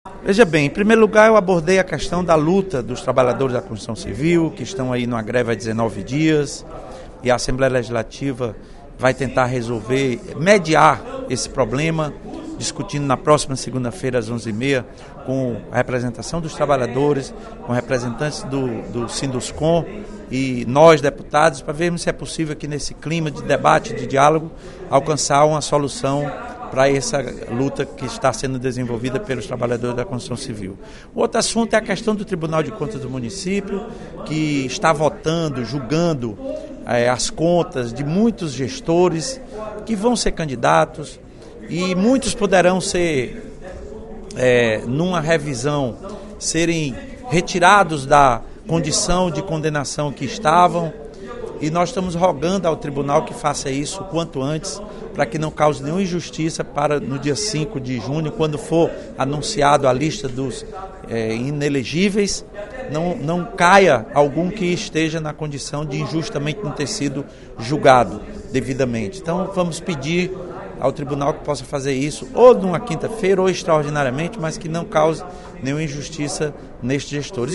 O deputado Lula Morais (PCdoB) pediu, nesta sexta-feira (25/05) em sessão plenária, agilidade no julgamento de processos contra gestores municipais que pretendem ser candidatos. O parlamentar disse que o TCM precisa se posicionar até o fim deste mês, uma vez que a lista dos inelegíveis sai no dia 5 de junho.